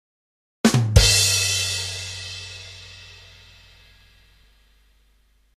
Comedy Punchline Rimshot.mp3
comedy-punchline-rimshot.mp3